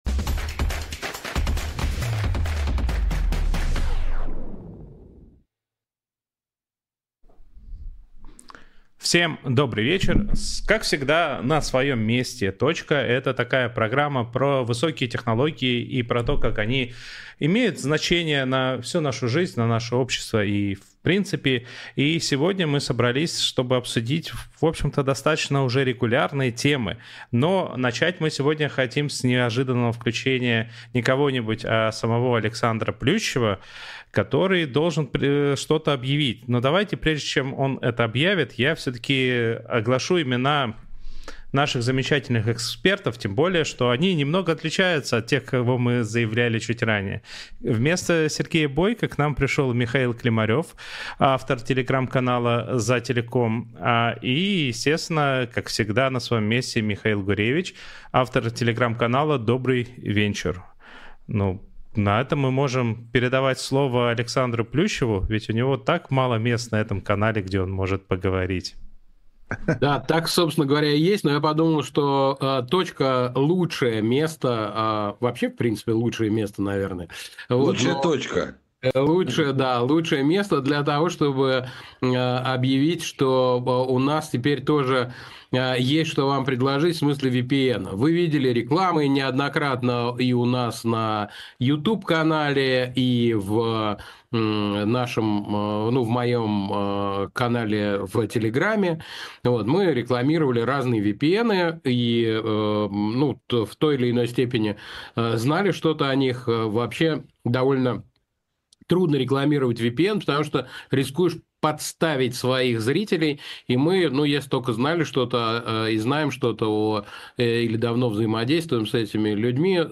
Александр Плющев журналист